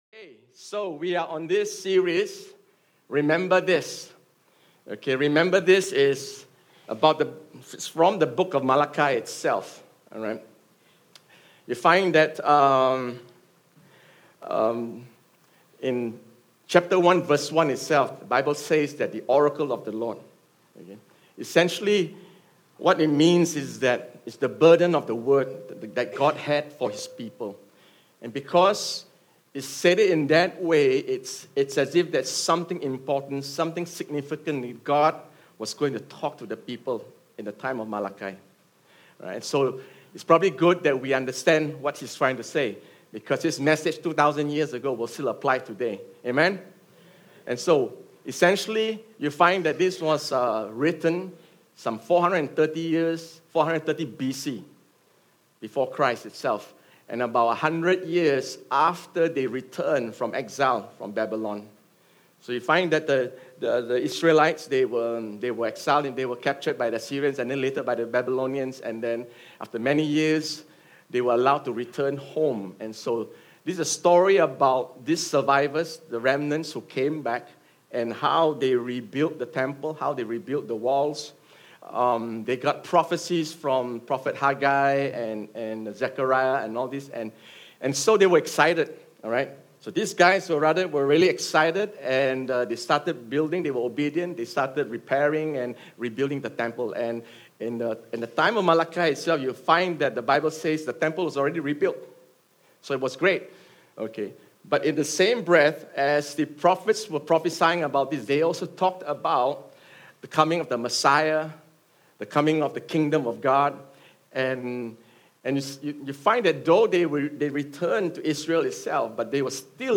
Sermon_RememberThis_3Jul19.mp3